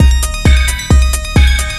TECHNO125BPM 25.wav